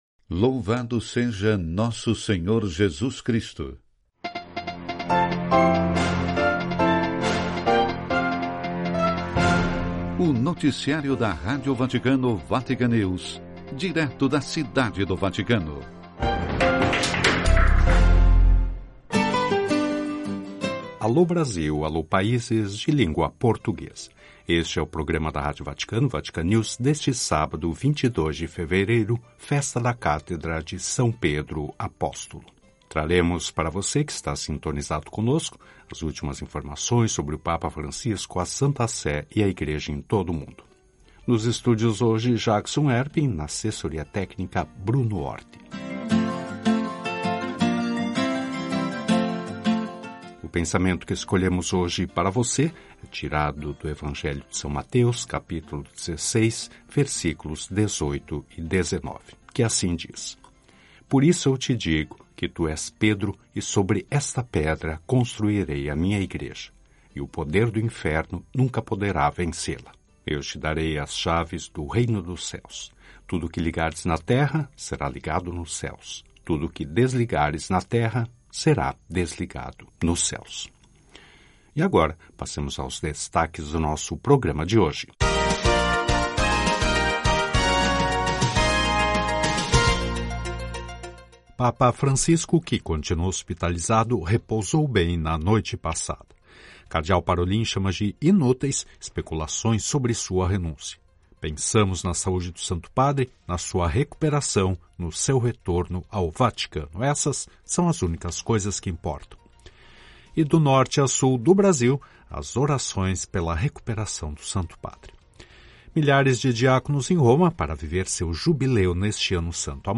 Noticiário (12:00 CET).